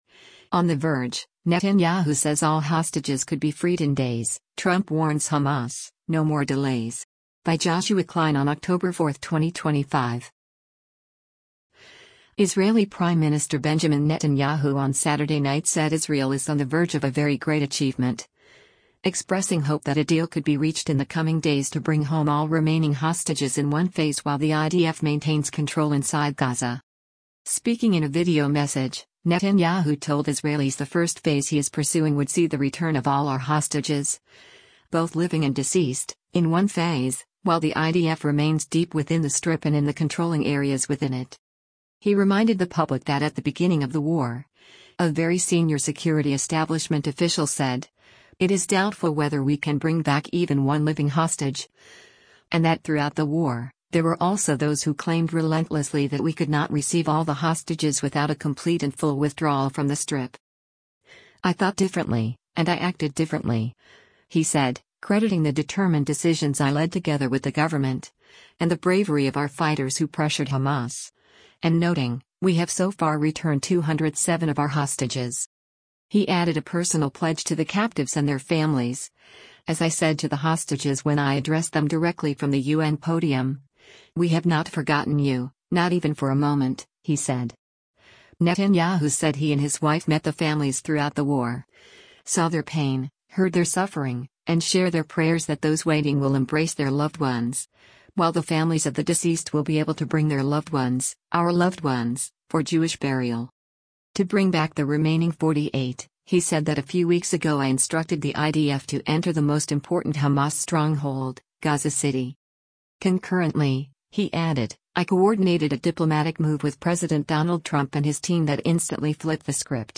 Speaking in a video message, Netanyahu told Israelis the first phase he is pursuing would see “the return of all our hostages, both living and deceased, in one phase, while the IDF remains deep within the Strip and in the controlling areas within it.”